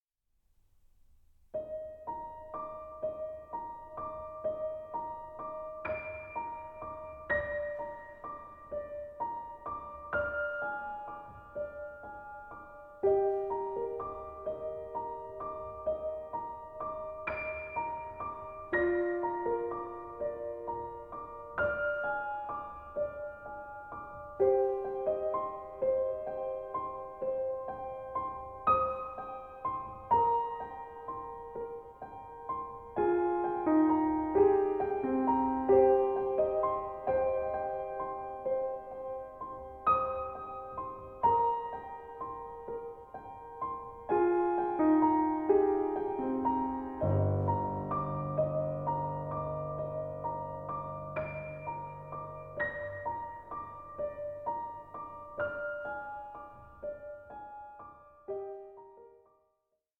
organist and keyboard player